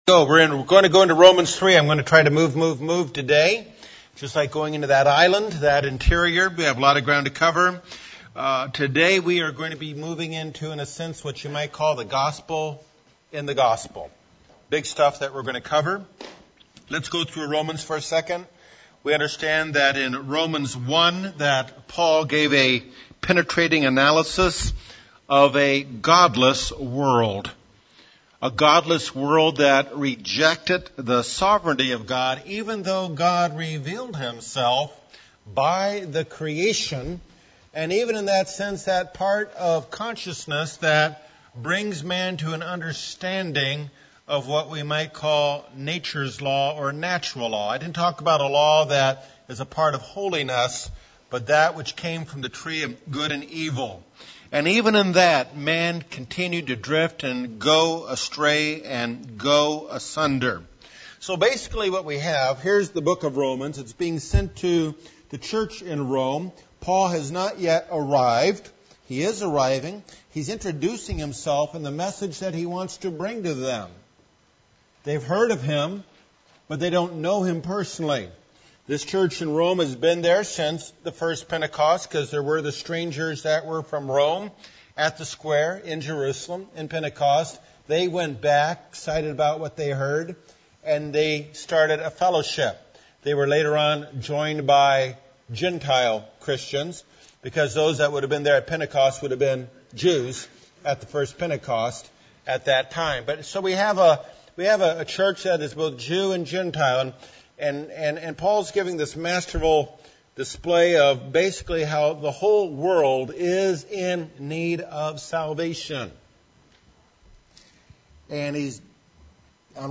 Ongoing Bible study of the book of Romans.